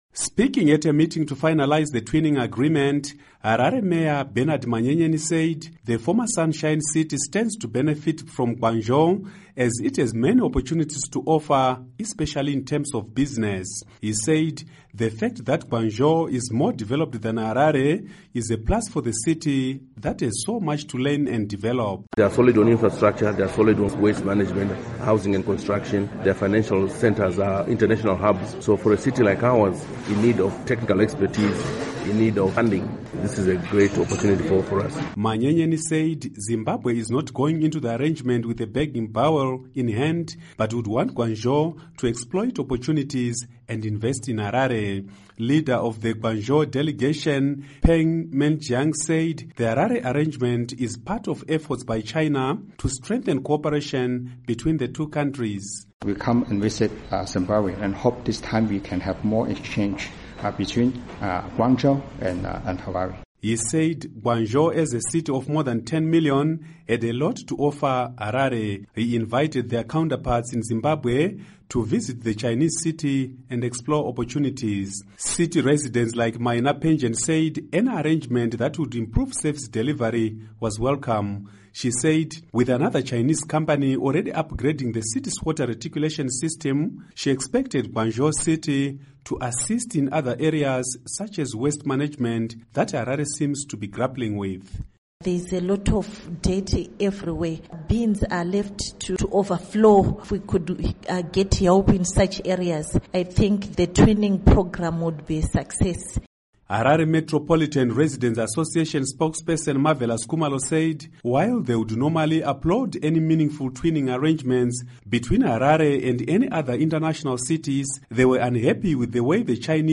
Report on Harare, Guangzhou Twinning